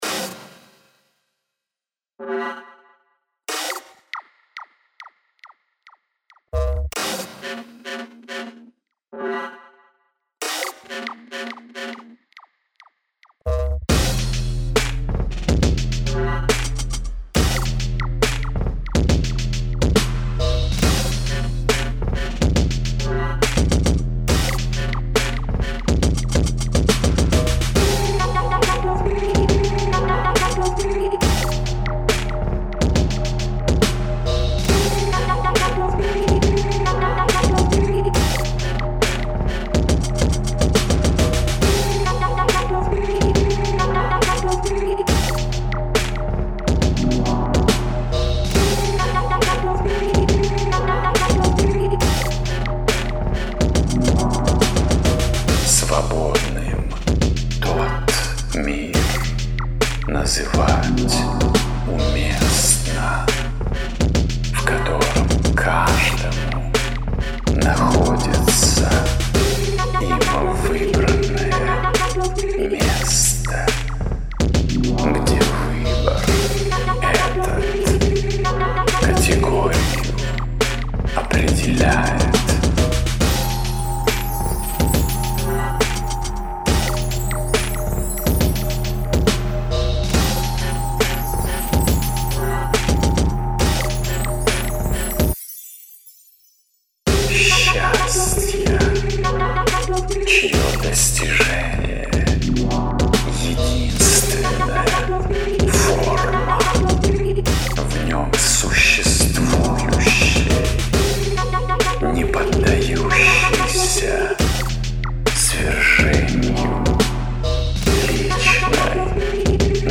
Booty Bass
Tweaks and removed the russian vocals.